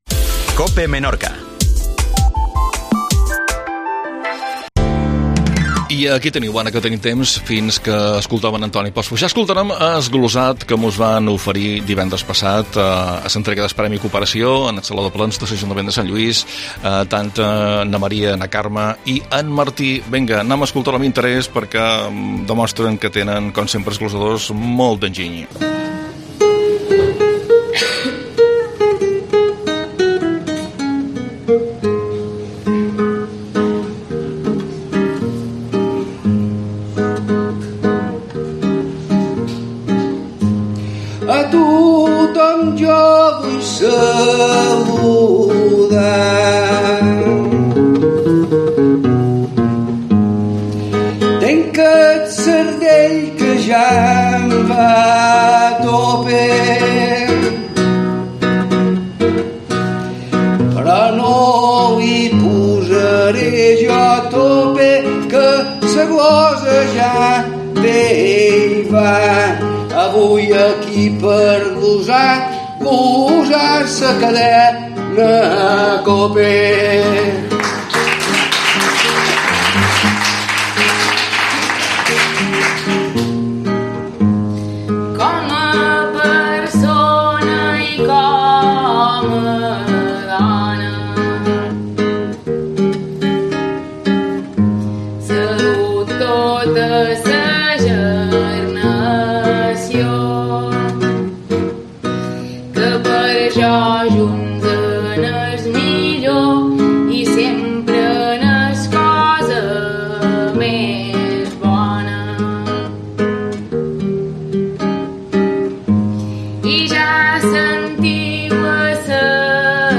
AUDIO: Glosat a l'entrega del Premi Cooperacio 2023.